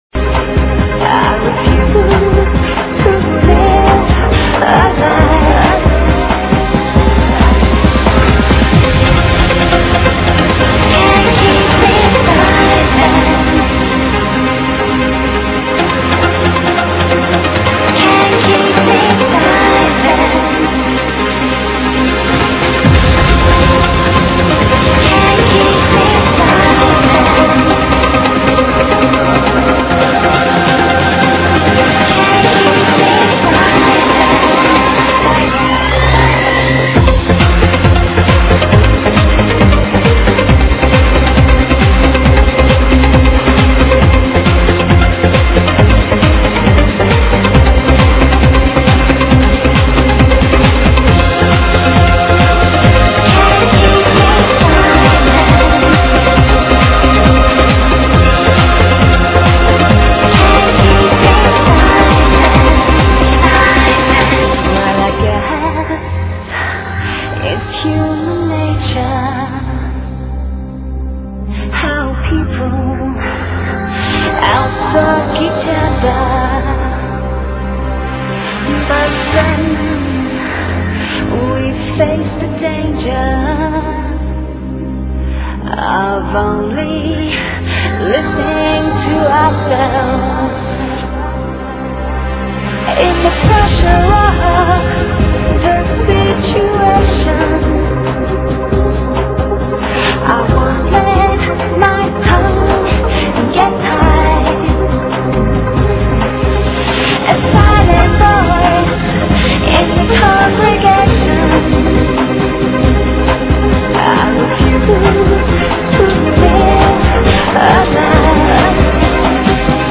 Please help ID this track (vocal trance)